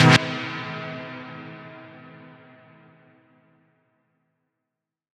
Rev Stab.wav